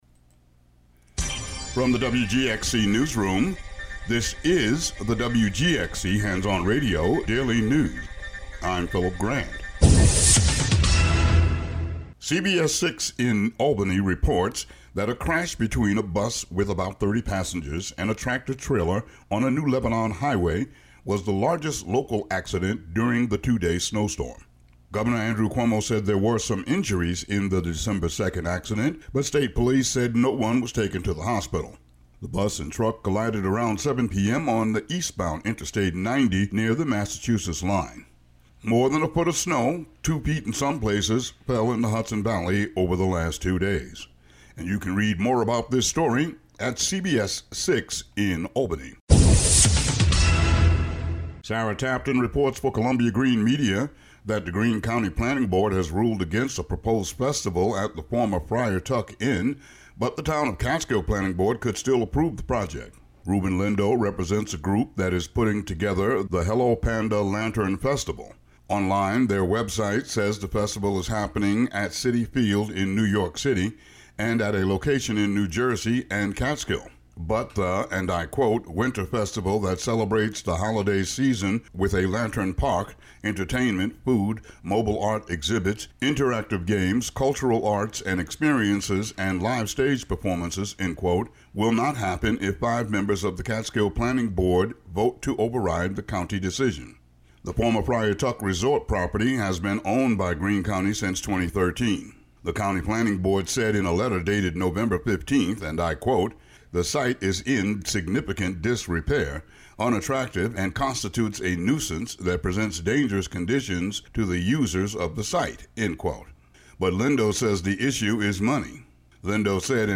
The audio version of the local news for Tue., Dec. 3.